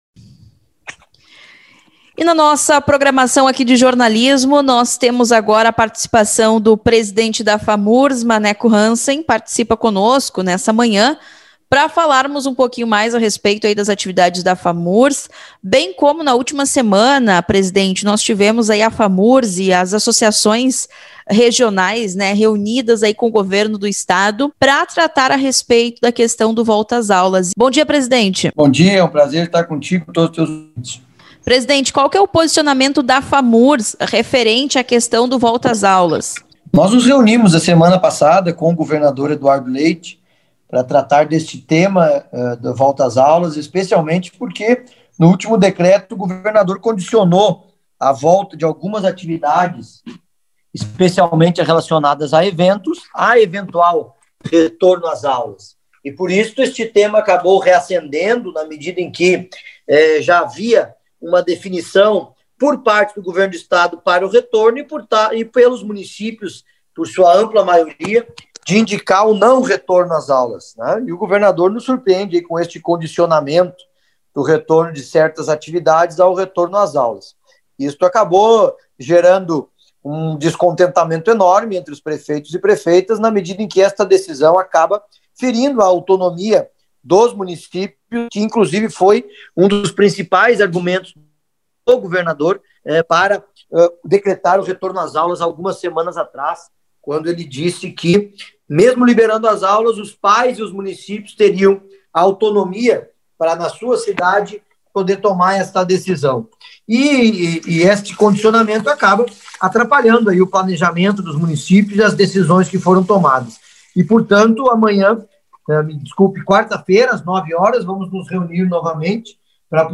O presidente da Famurs e Prefeito de Taquari Maneco Hassen, fala com exclusividade sobre o assunto.